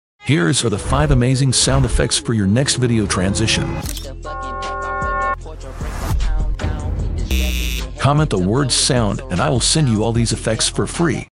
Here's the 5 amazing sound effects for your next Video Transition